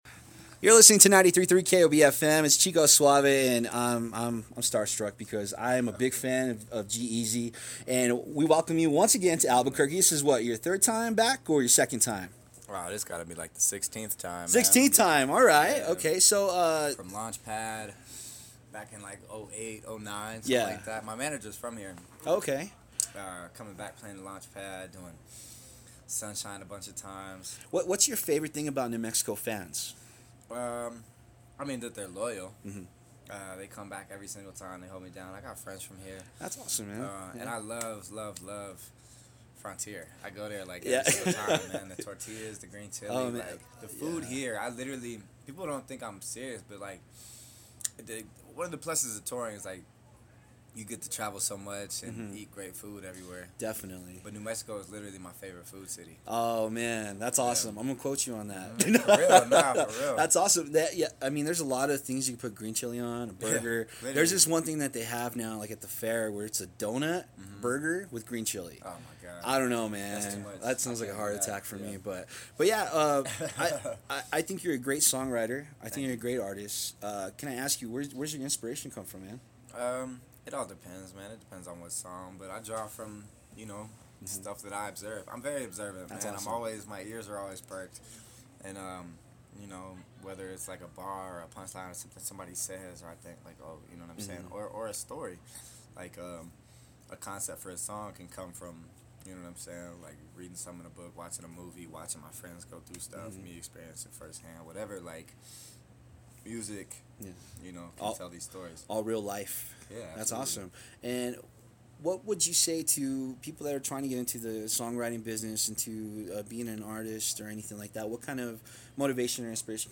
G-Eazy interview 07/01/16